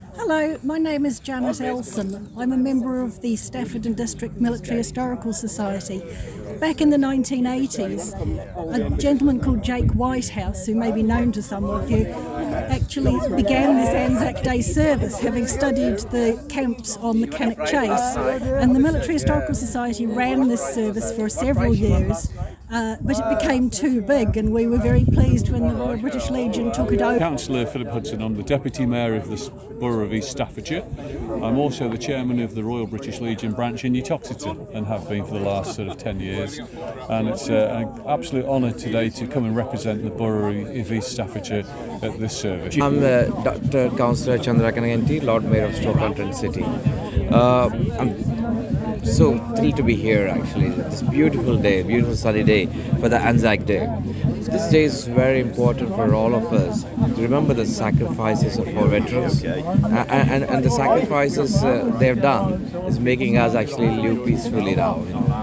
Each of the following links connect to audio recordings of some of the many dignitaries who attended the Service:
The Deputy-Mayor of East Staffordshire Borough, Councillor Phillip Hudson
The Right Worshipful the Lord Mayor of Stoke, Councillor Dr Chandra Kanneganti